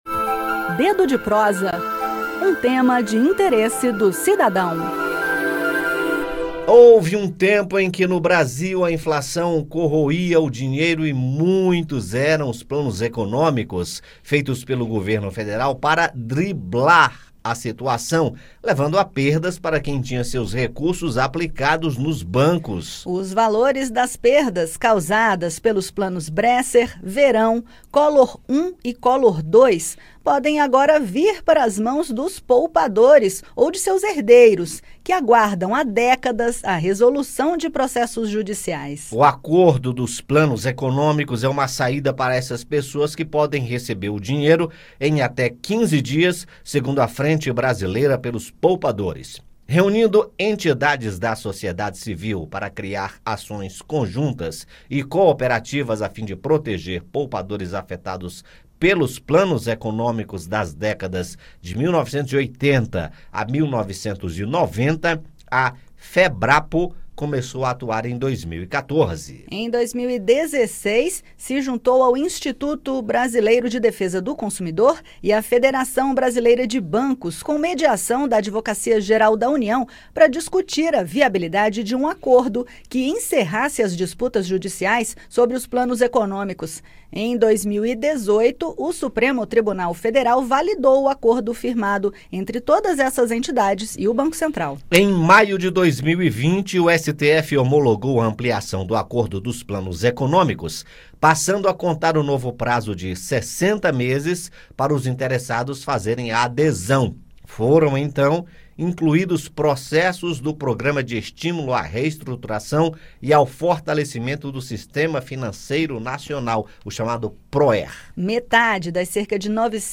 Os valores das perdas causadas pelos planos Bresser, Verão, Collor I e Collor II podem agora vir para as mãos dos poupadores, ou de seus herdeiros. No bate-papo, entenda o acordo dos planos econômicos e onde obter dados confiáveis para saber se você é herdeiro e receber os valores.